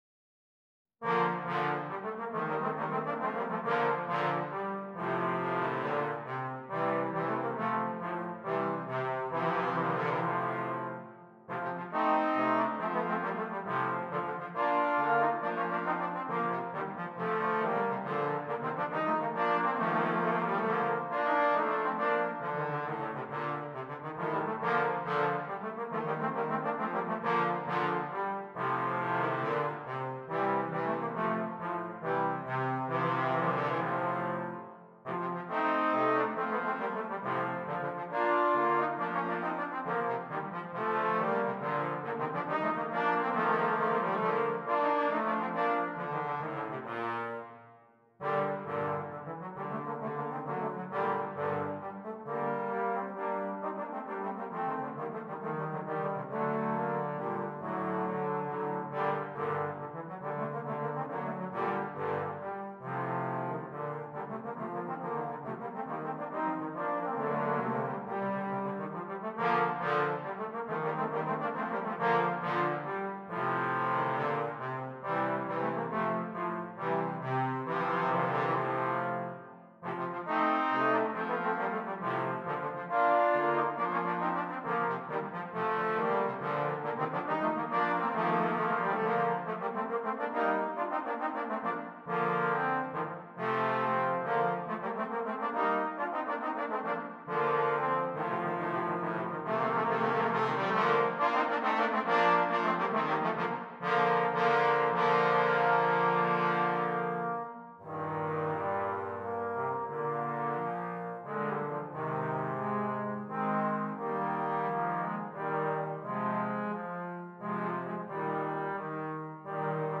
3 Trombones